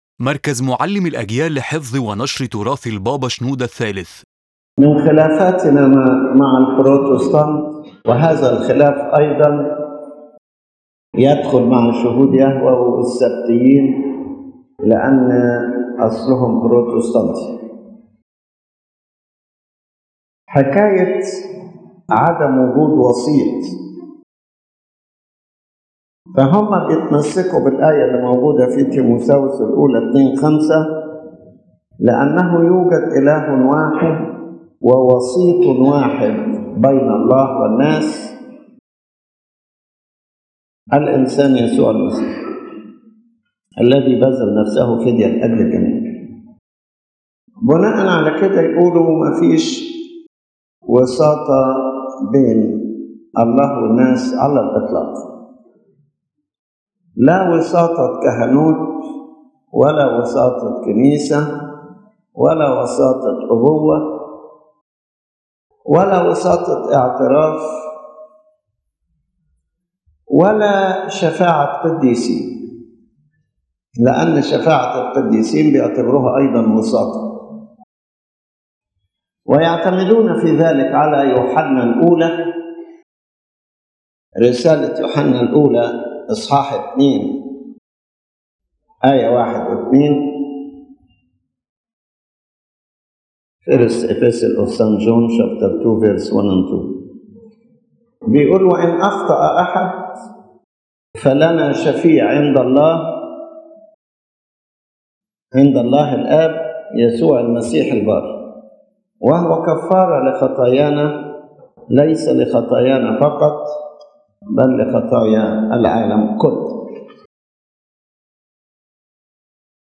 The lecture shows that the word “mediation” has a double meaning: on the one hand, the redemptive or atoning mediation which Christ alone performed, and on the other hand, the functional and mediatory mediation that the Church and its structure and ministers exercise in the life of believers. The speaker affirms that redemption and atonement are not performed by anyone other than Christ, but there are other mediations necessary for salvation and grace to reach people.